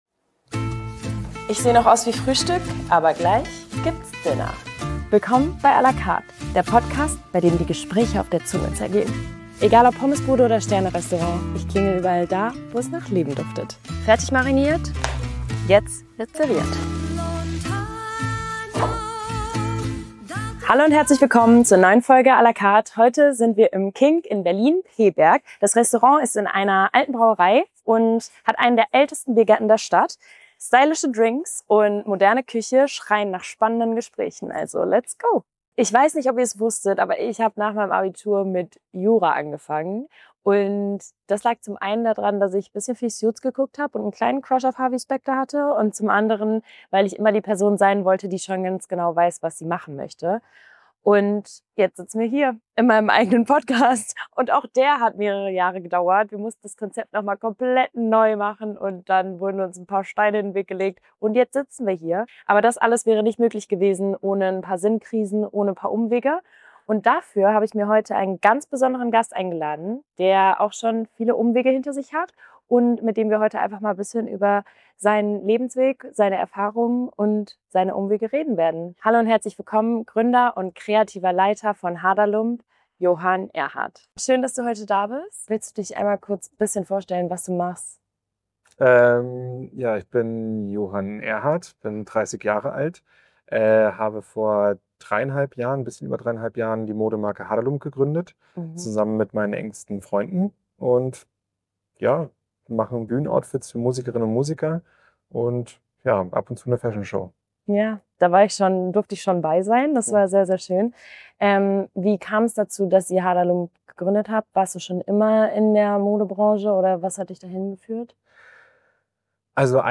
Bei Weisswein und Pasta sprechen die beiden in der sechsten Folge „à la Carte“